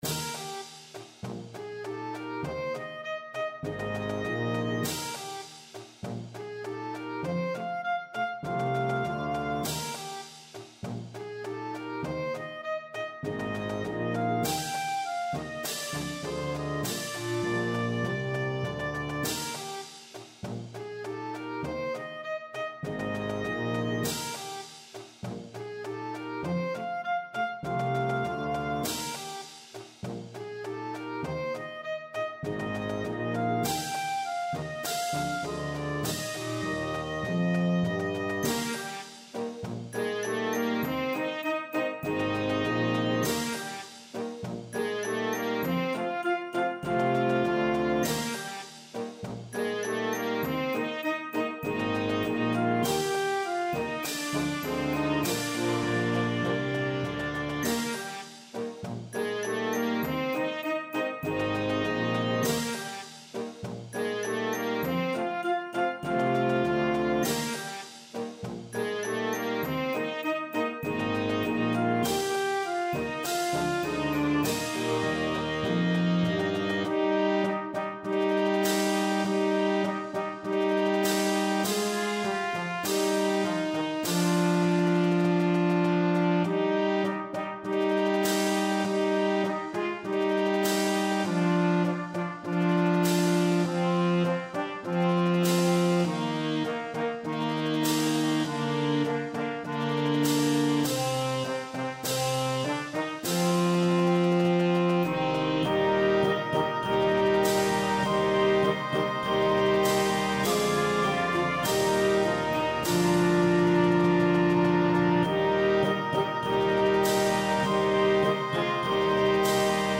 is a simple march in ABA form